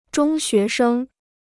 中学生 (zhōng xué shēng) Free Chinese Dictionary